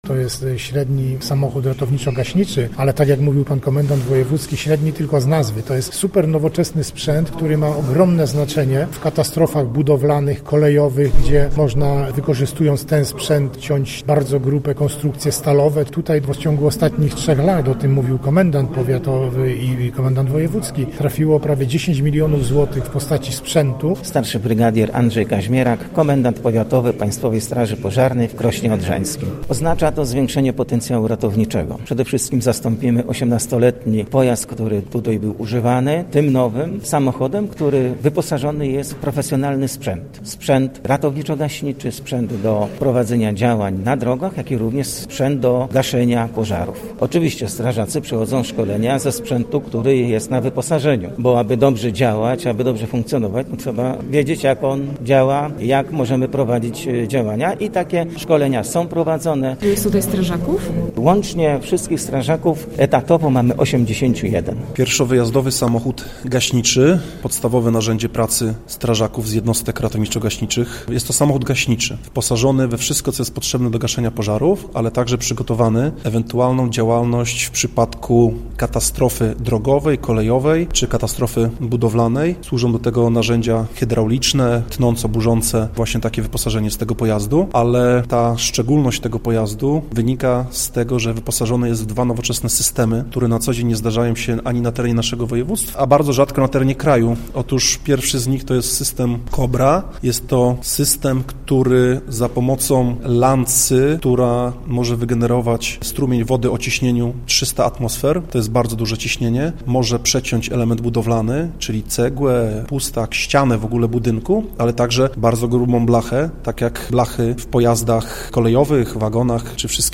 Wojewoda lubuski Władysław Dajczak mówi, że jednostka od trzech lat jest doposażana, jak dodaje, to już czwarty wóz, który trafił do Krosna Odrzańskiego: